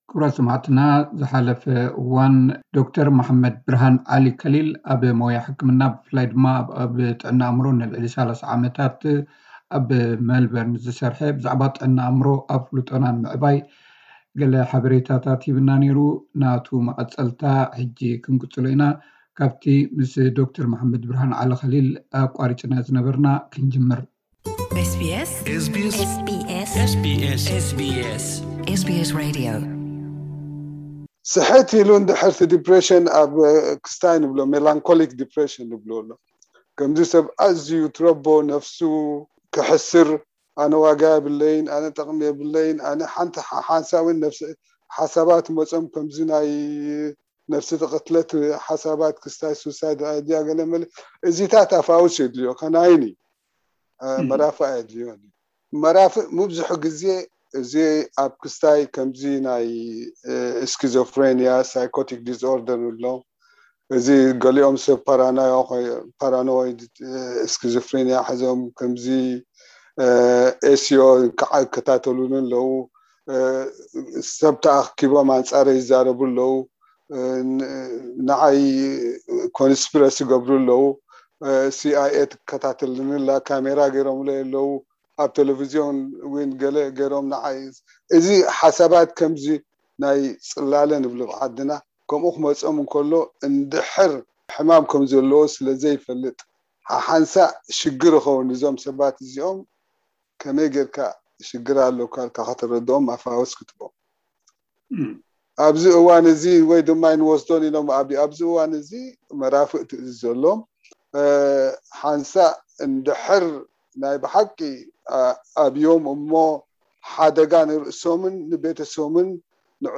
ብዛዕባ ጥዕና ኣእምሮ ኣፍልጦና ንምዕባይ፡ ብኣጋ ስጉምቲ ንምውሳድን ምሳና ዝርርብ ጌሩ ኔሩ፡ ካብቲ ዝሓለፈ ዝቐረበ ተወሳኺ ሕቶታትን መልሲን ቀሪቡ ኣሎ።